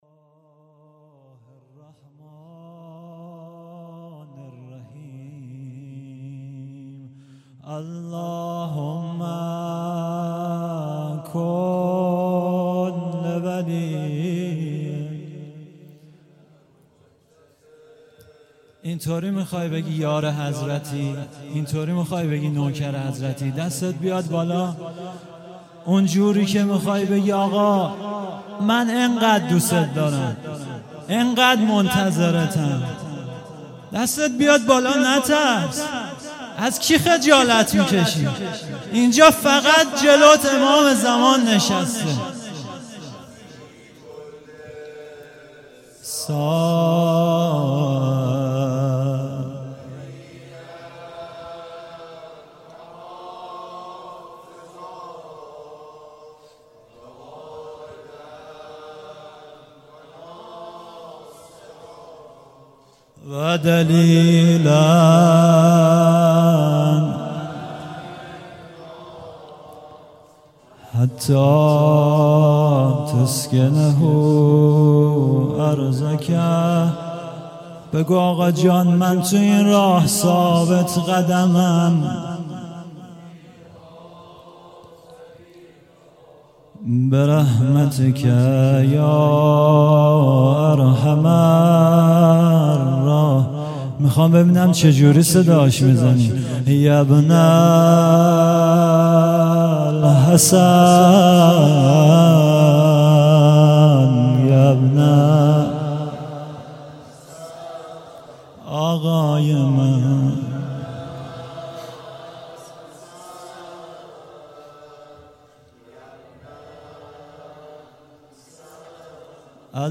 خیمه گاه - حضرت قاسم بن الحسن(سلام الله علیهما) - روضه